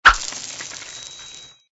TL_marbles.ogg